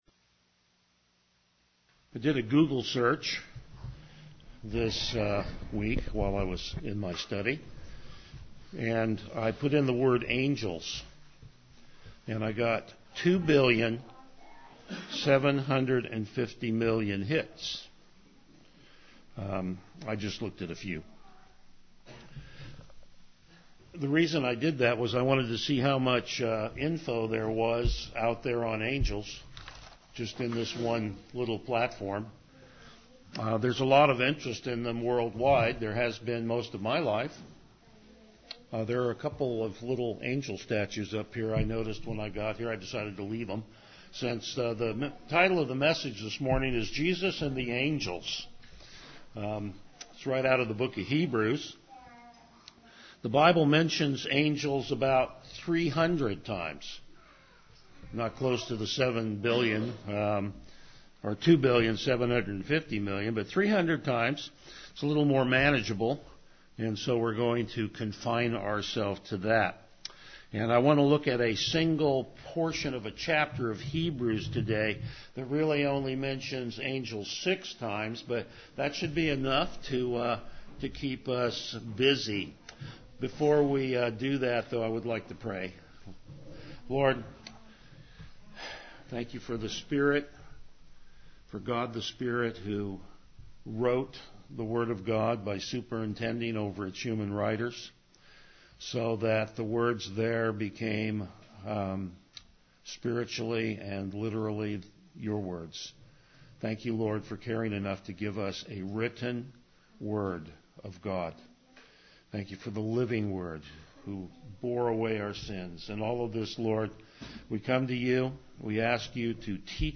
Passage: Hebrews 1:4-14 Service Type: Morning Worship Topics